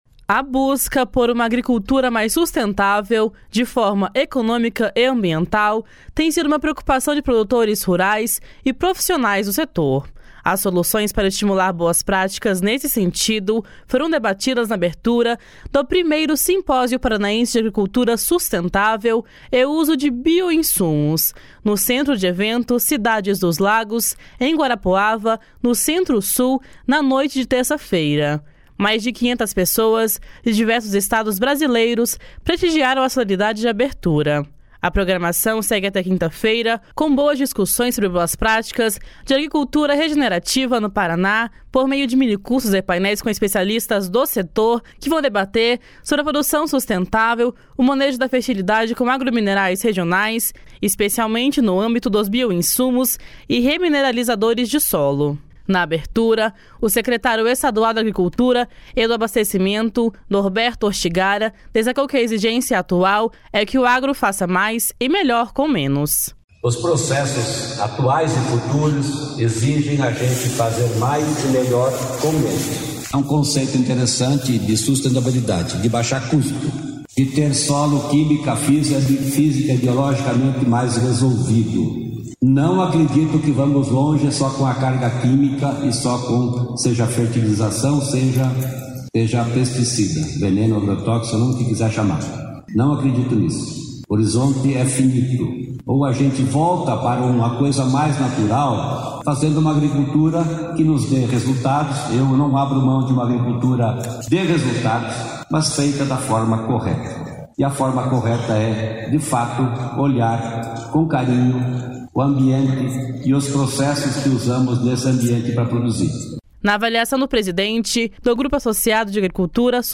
Na abertura, o secretário estadual da Agricultura e do Abastecimento, Norberto Ortigara, destacou que a exigência atual é que o agro faça mais e melhor com menos. // SONORA NORBERTO ORTIGARA //